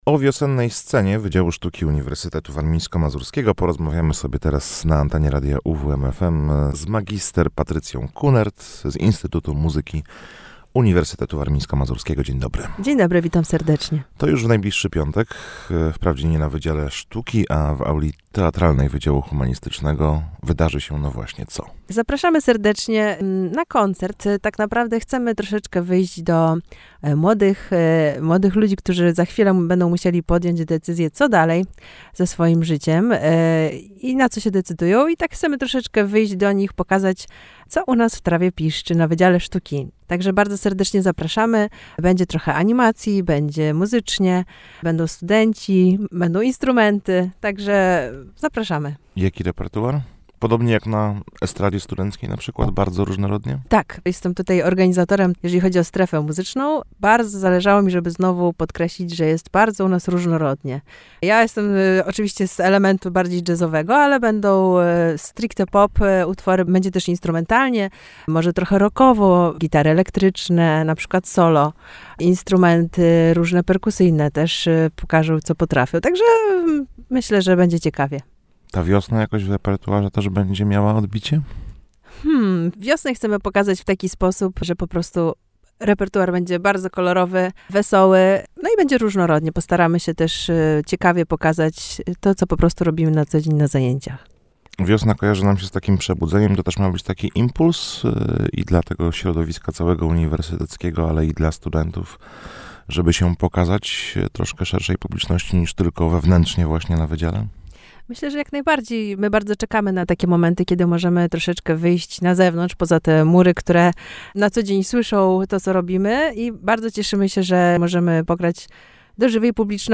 A o wydarzeniu w studiu Radia UWM FM opowiadała